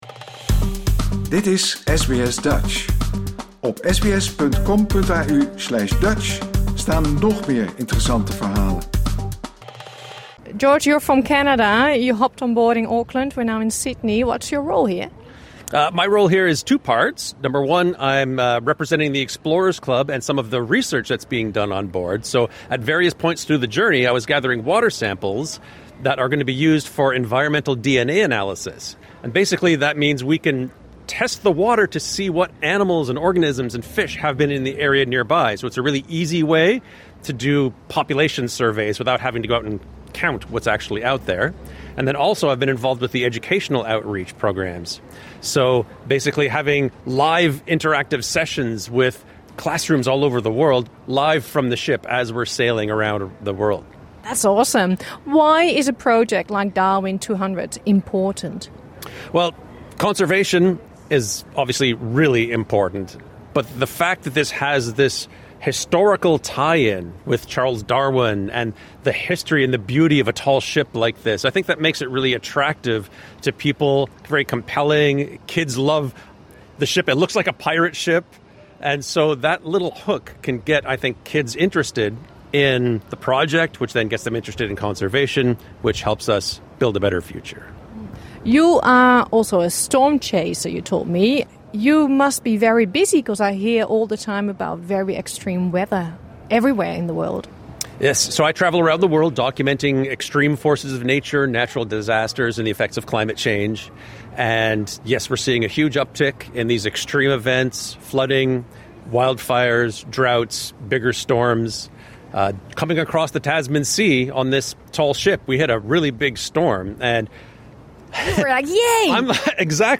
Dit interview is in het Engels.